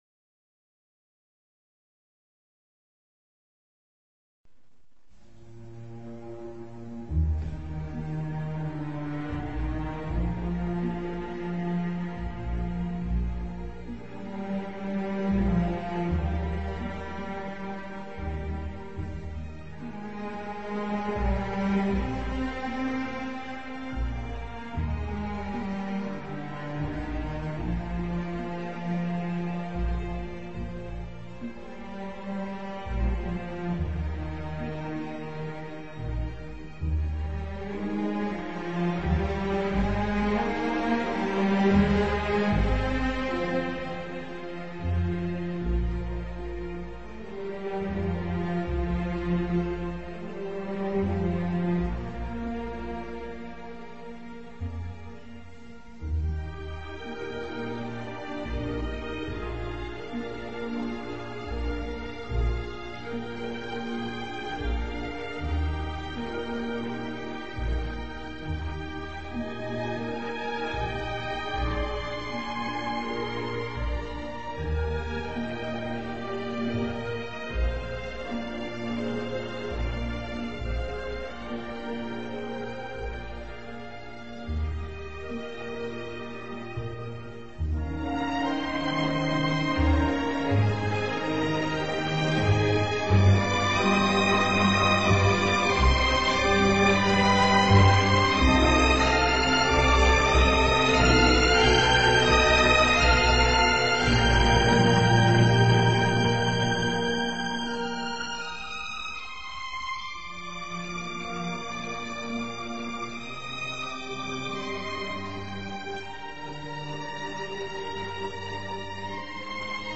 Chopin-Tristesse-Orchestral.mp3